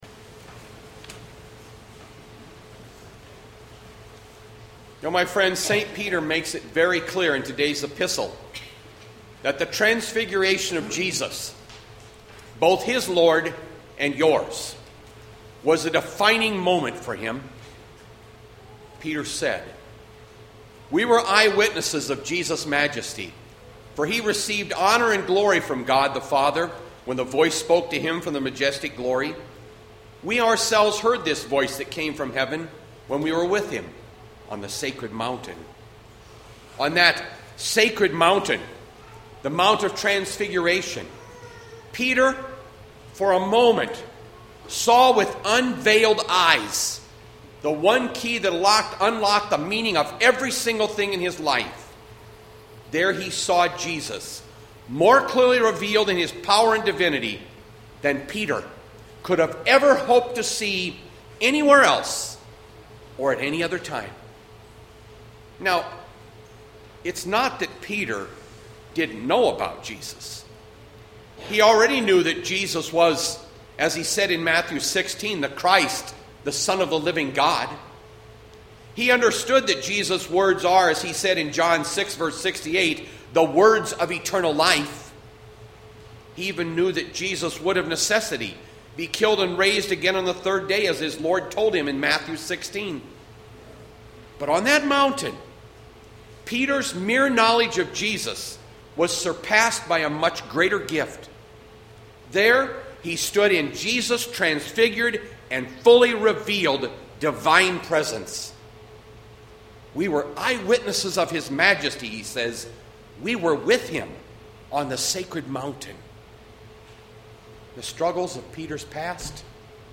Bethlehem Lutheran Church, Mason City, Iowa - Sermon Archive Feb 23, 2020